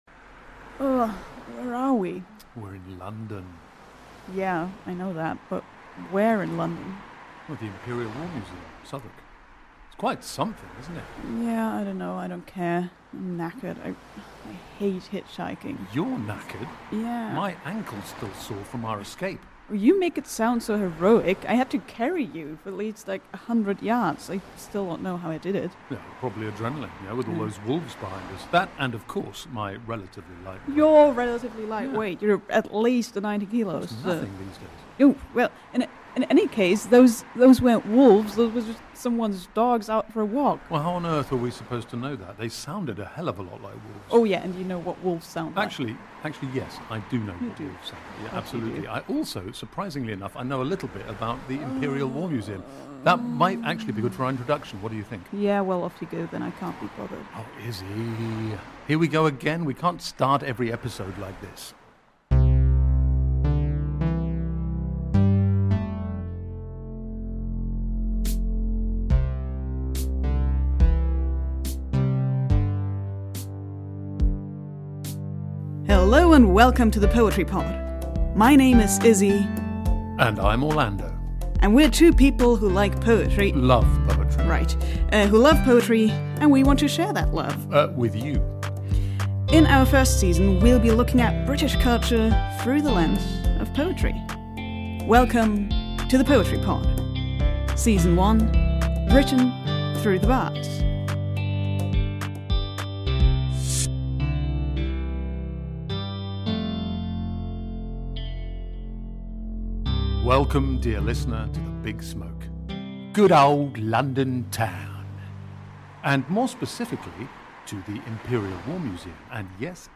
Interview guest